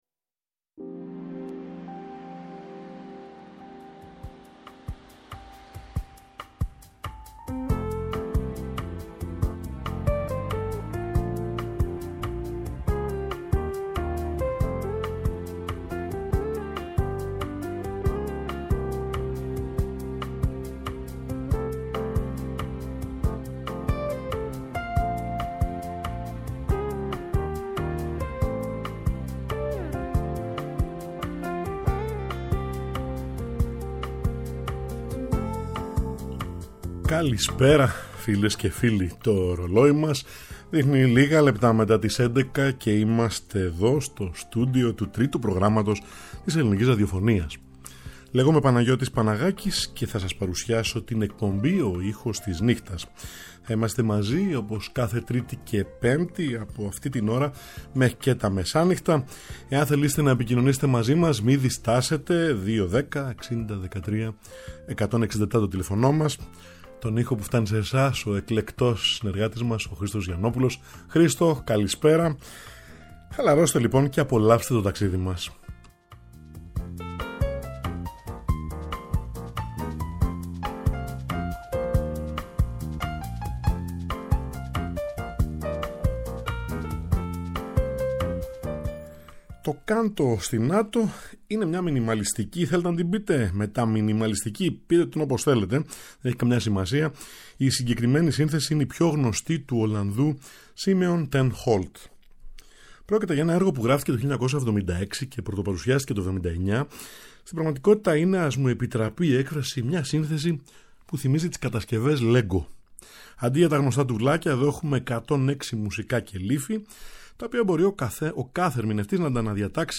Κάθε Τρίτη και Πέμπτη στις έντεκα, ζωντανά στο Τρίτο Πρόγραμμα